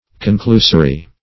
Conclusory \Con*clu"so*ry\, a.
conclusory.mp3